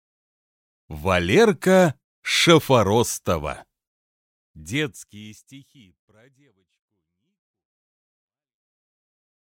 Аудиокнига Детские стихи про девочку Нику, маленькую Бонни, про ленивого кота Эльфика и про многих других | Библиотека аудиокниг